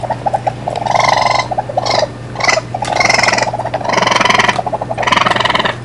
Звуки енота
Звук скуления енота